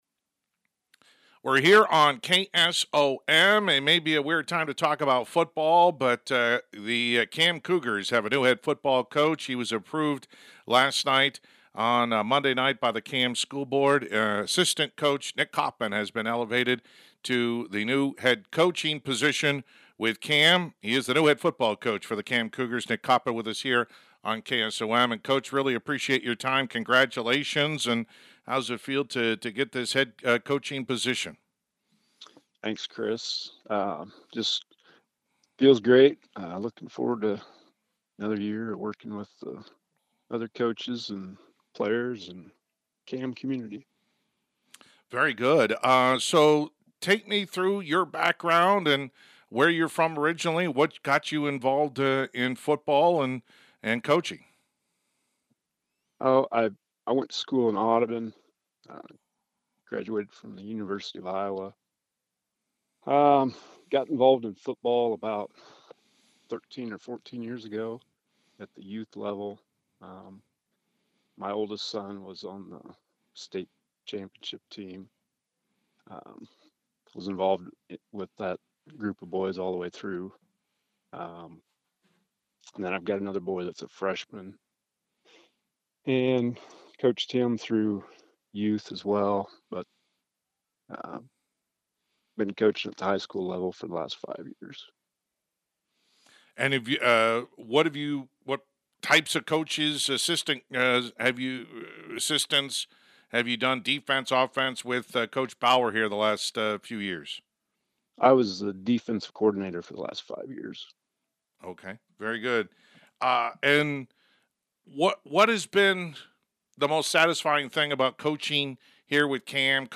Complete Interview